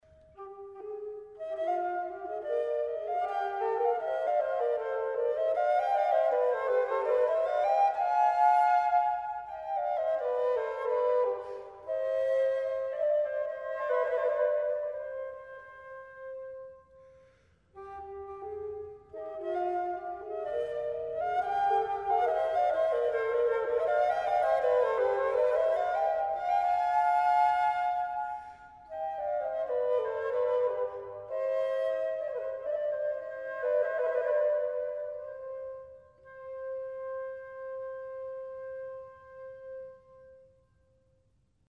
This flute is easy from the d1 (also f3) to the a3 (which can be blown with a whisper!!!).